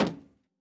added stepping sounds
LowMetal_Mono_03.wav